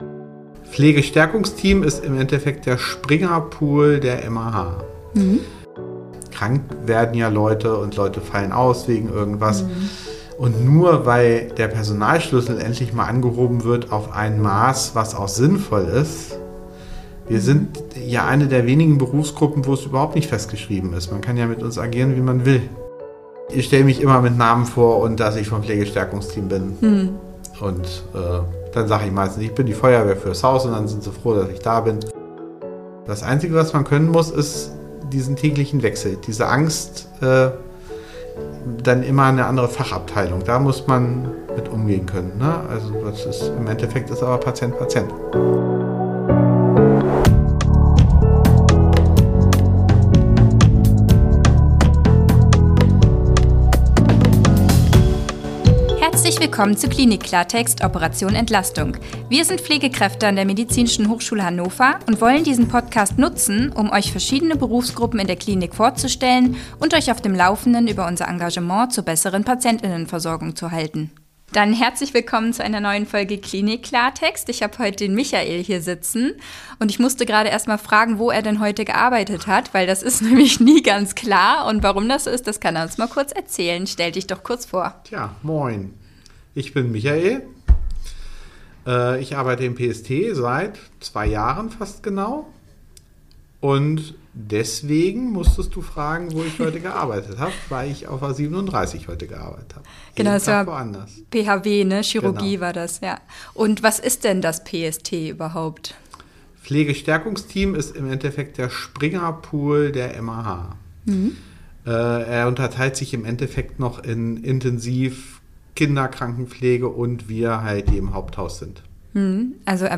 Pflegestärkungsteam – Ein Interview